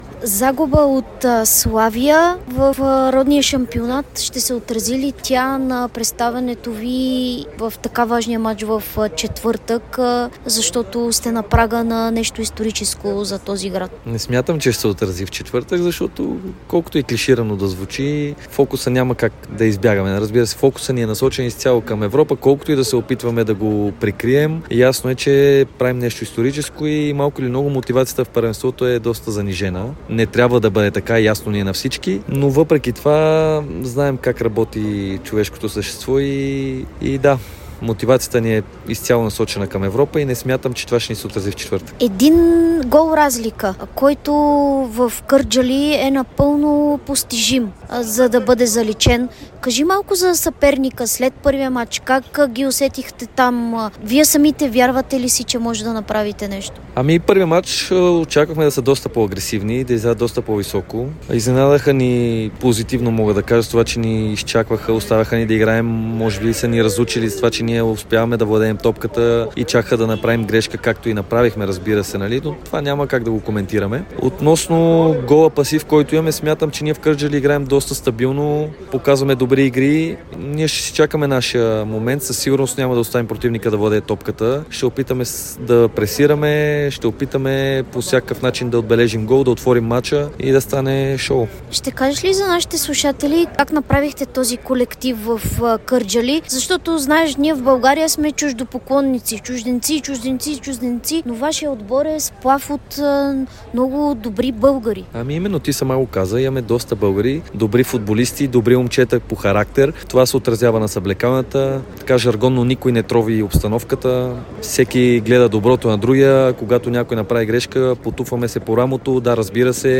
даде интервю за Дарик радио след загубата с 0:2 от Славия в „Овча купел“ и говори за предстоящия мач-реванш от плейофната фаза на Лигата на конференциите срещу Ракув, където родопчани трябва да наваксат гол пасив.